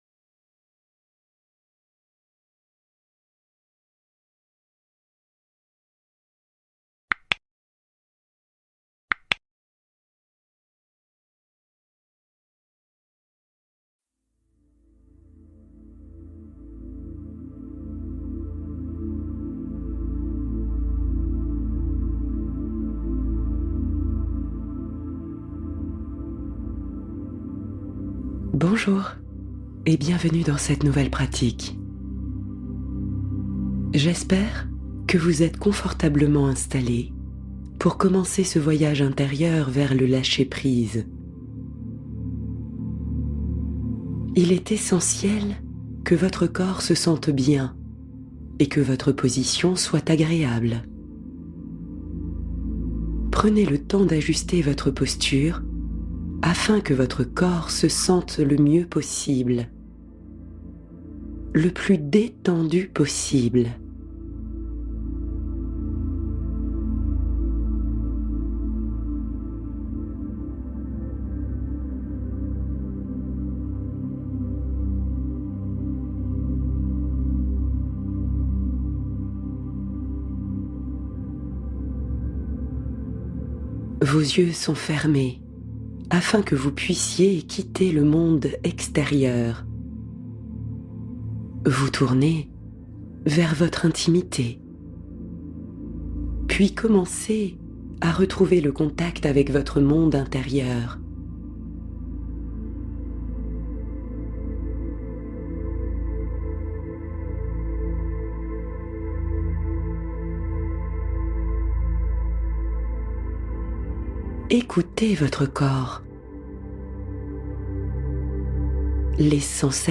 Lâcher-prise : Relaxation profonde pour un endormissement serein